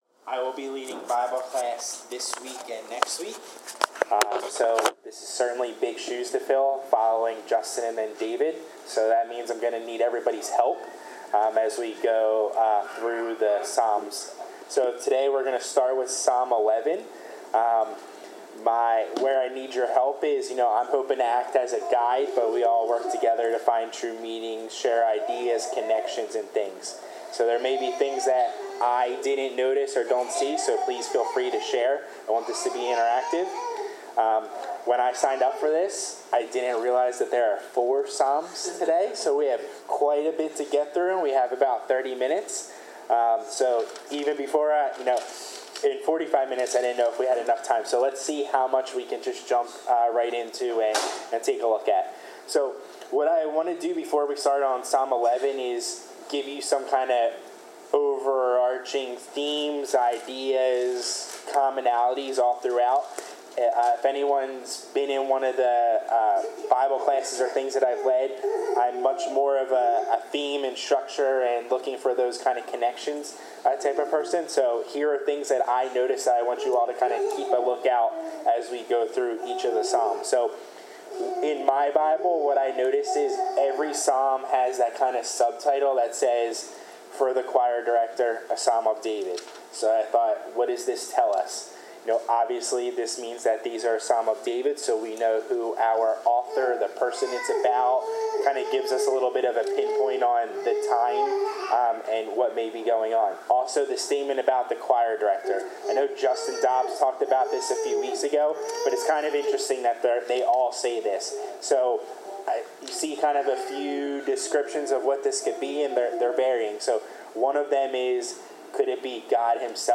Bible class: Psalms 11-14
Service Type: Bible Class Topics: Faith , Jesus , Justice , Obedience , Patience , Promises of God , Righteousness , Trusting in God , Truth , Wrath of God